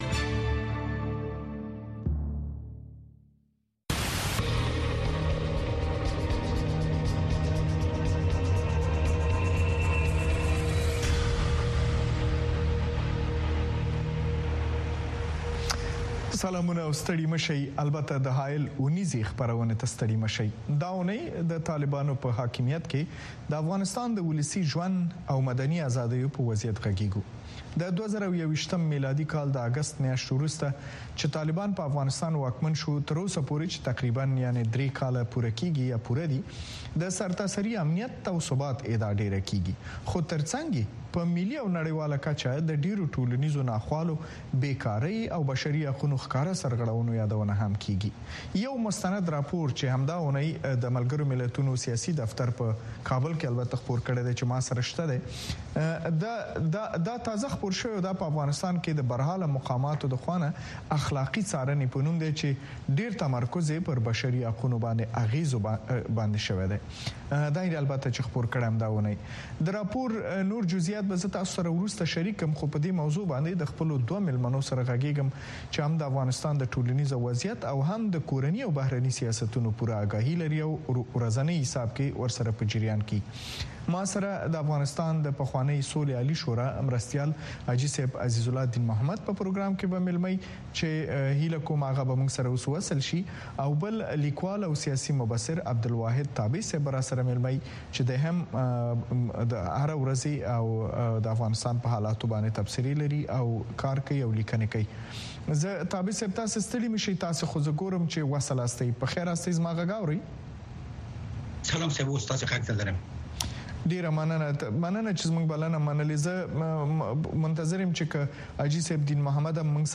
کاوشگری و ژرف نگری رویدادهای داغ روز در افغانستان زیر سلطۀ طالبان را در برنامۀ حایل از صدای امریکا دنبال کنید. وضعیت افغانستان، چگونگی رویدادها، بحث در مورد راه‌حل مشکلات و بن بست‌های موجود در آن کشور از زبان کارشناسان، تحلیلگران و مسوولان هر جمعه شب ساعت ۷:۳۰ به وقت افغانستان